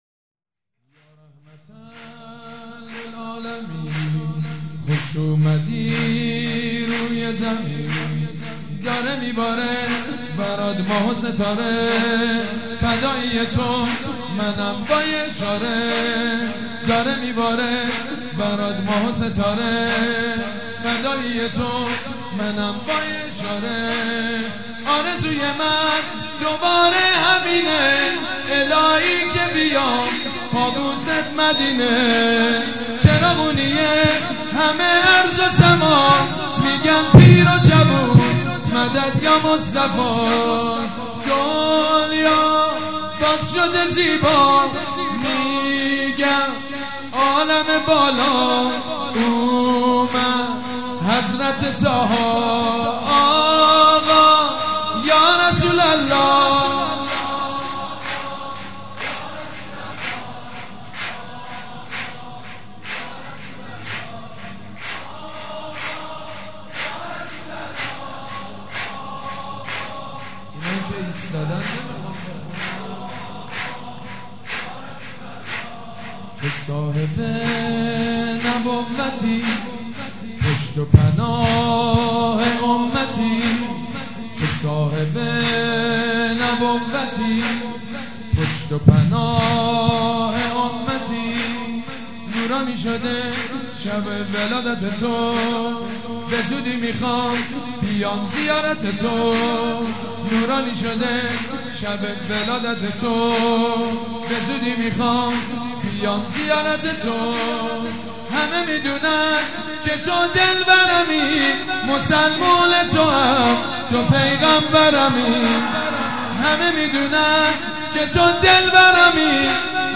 مراسم میلاد پیامبر خاتم(ص)و حضرت امام جعفر صادق(ع)
سرود